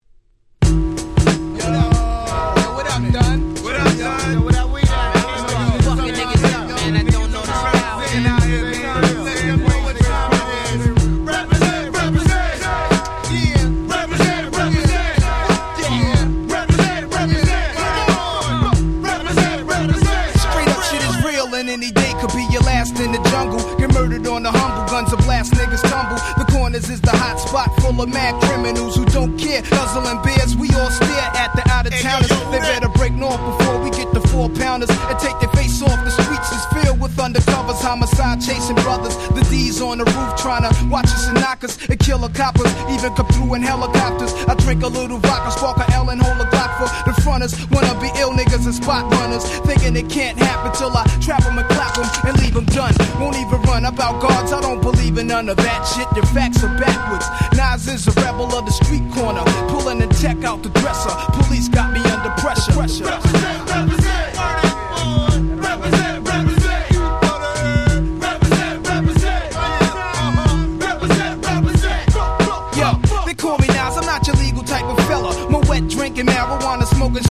99' Super Hit Hip Hop !!
Boom Bap